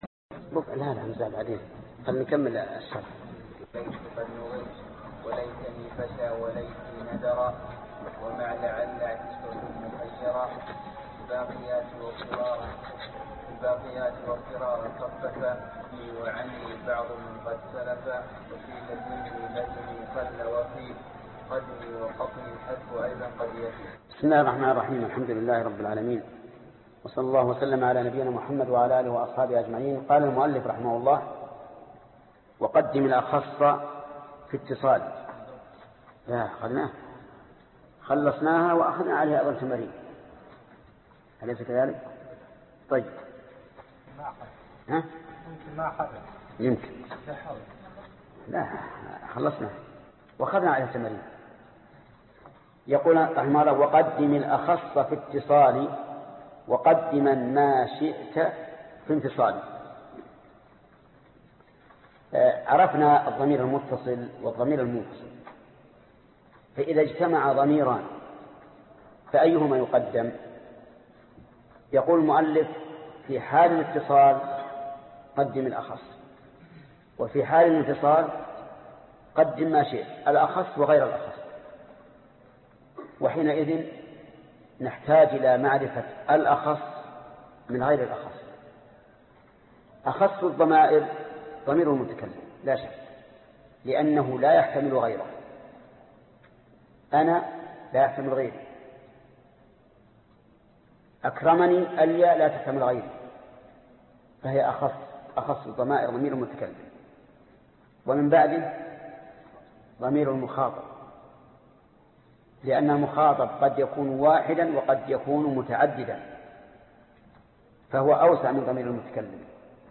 الدرس 54 النكرة والمعرفة 11- الابيات66 الي 68 ( شرح الفية بن مالك ) - فضيلة الشيخ محمد بن صالح العثيمين رحمه الله